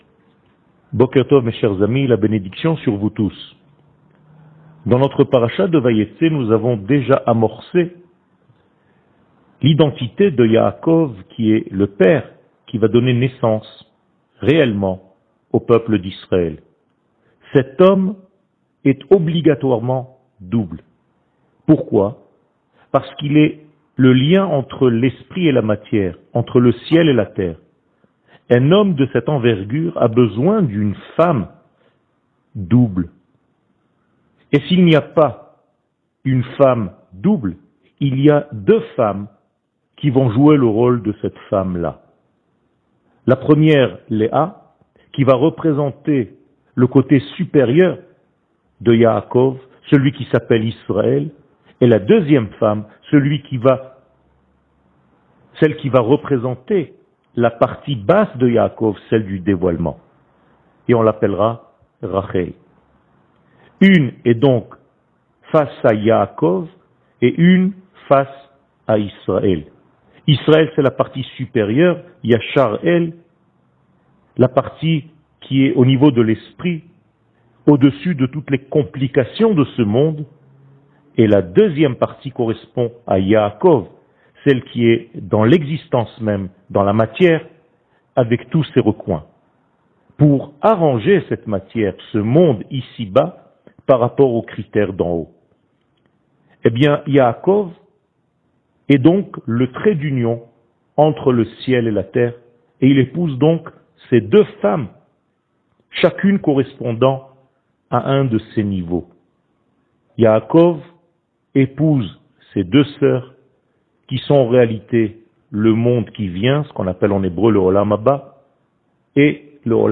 שיעור מ 11 נובמבר 2021